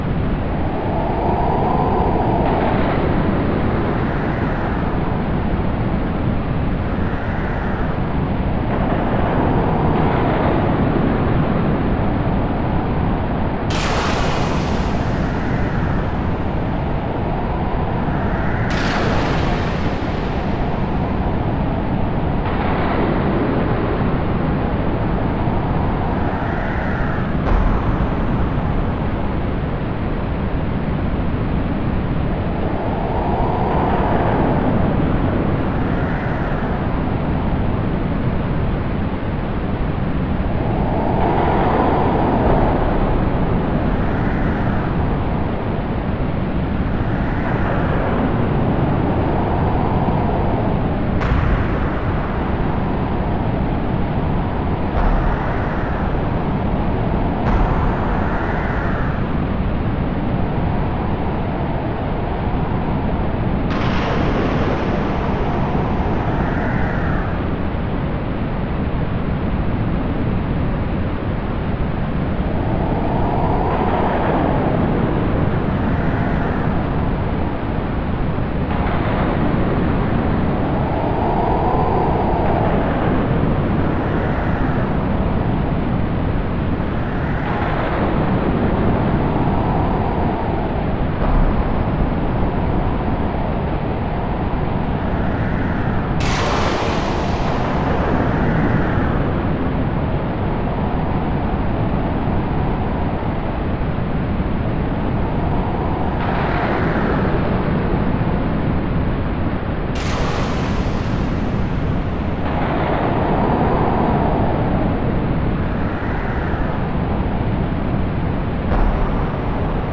This is an ambient atmospheric soundscape that I made in order to express my emotions. You should listen to it with good quality stereo headphones.